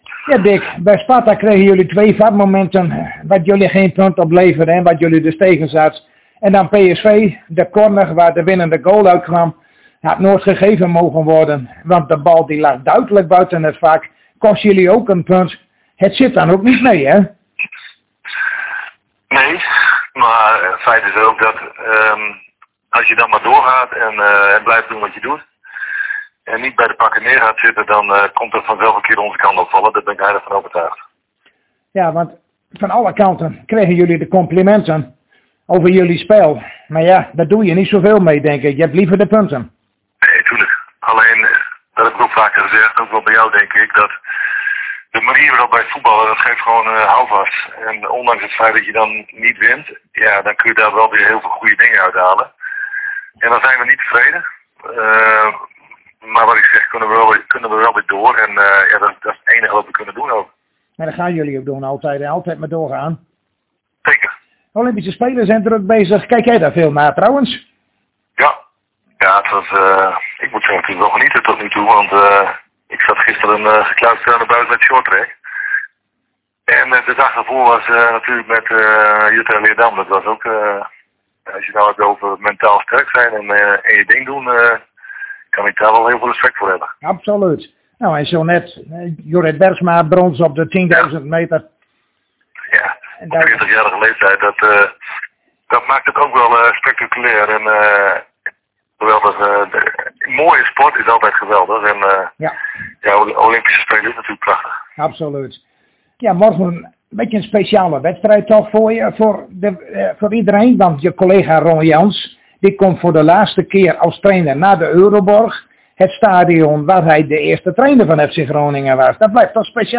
Zojuist spraken wij weer met trainer Dick Lukkien over de wedstrijd tegen FC Utrecht van morgenavond.